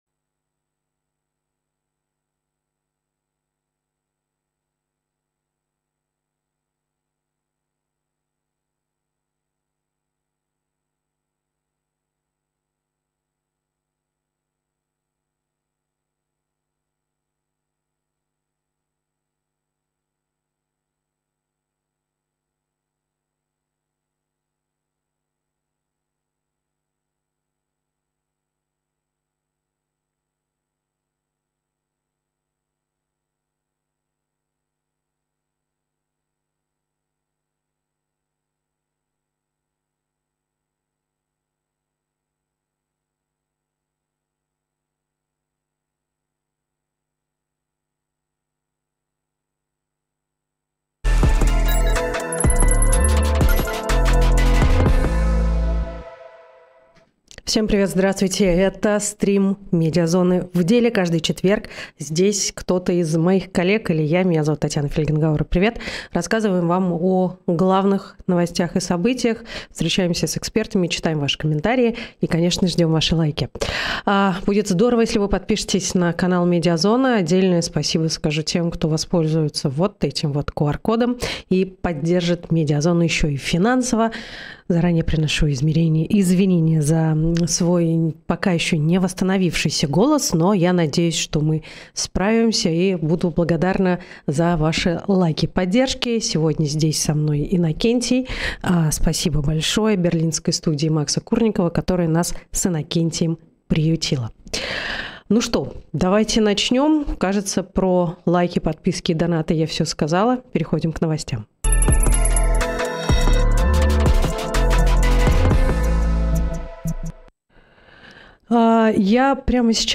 Стрим ведет Татьяна Фельгенгауэр
И конечно, ведущая стрима Татьяна Фельгенгауэр ответит на вопросы из ваших донатов.